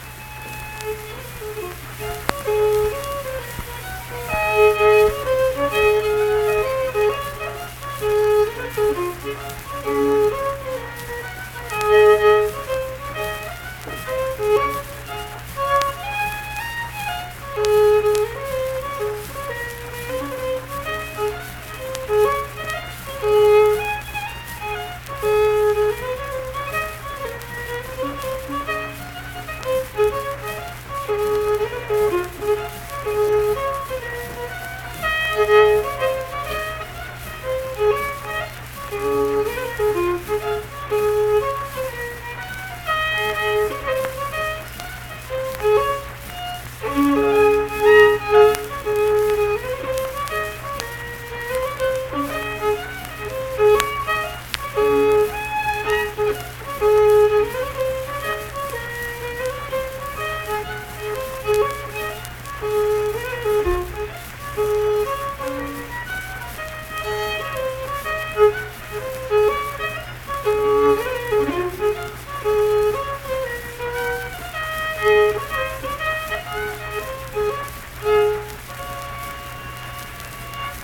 Unaccompanied fiddle music
Performed in Ziesing, Harrison County, WV.
Instrumental Music
Fiddle